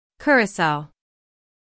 IPA: /ˈkjʊr.ə.saʊ/.
How to Pronounce Curaçao
Syllables: CUR · a · sao
curacao-us.mp3